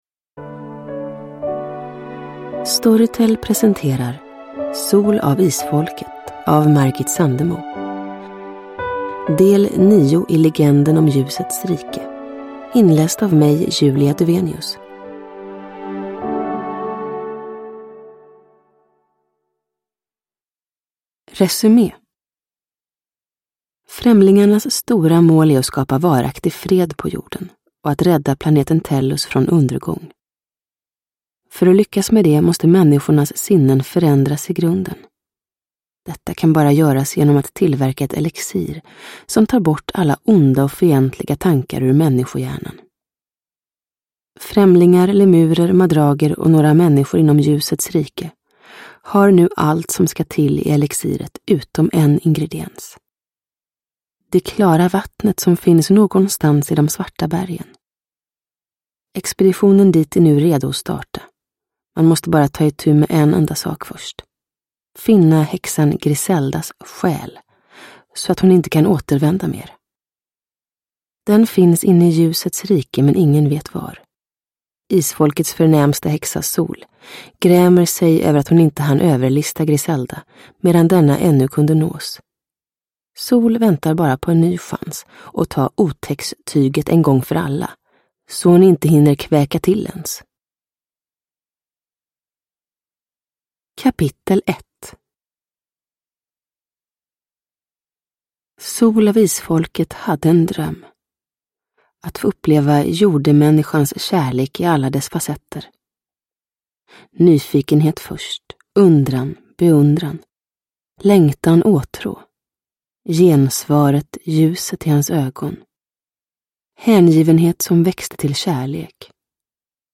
Sol av Isfolket – Ljudbok – Laddas ner
Uppläsare: Julia Dufvenius